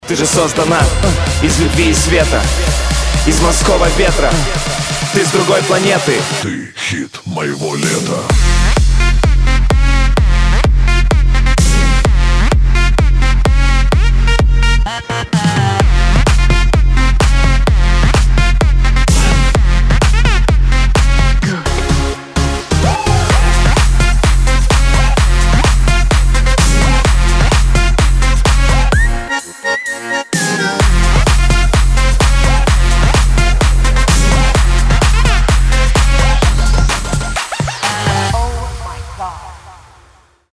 • Качество: 320, Stereo
веселые